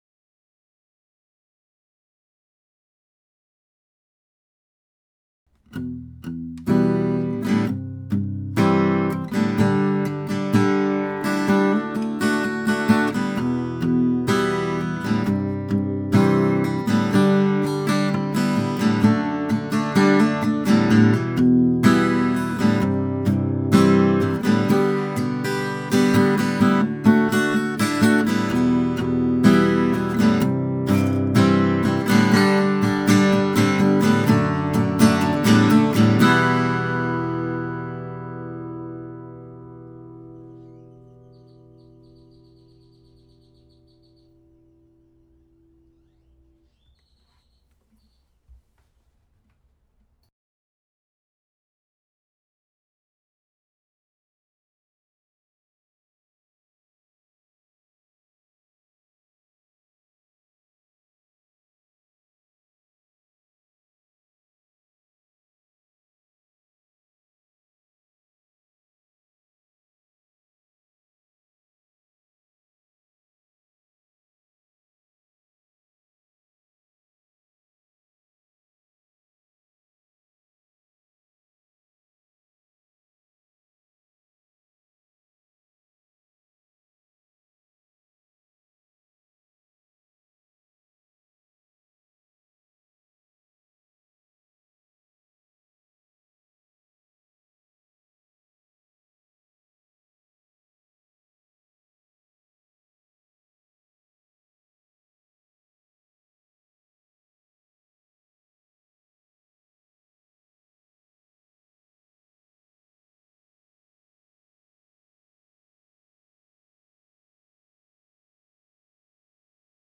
Acoustic Guitar
These MP3 files have no compression, EQ or reverb -- just mic'd through a pair of Blue Dragonfly mics, into a Presonus ADL 600 preamp into a Rosetta 200 A/D converter.
Here are some additional MP3 clips of this guitar using an assortment of different mics:
Schoeps CMT 341: